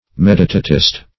Meditatist \Med"i*ta`tist\, n. One who is given to meditation.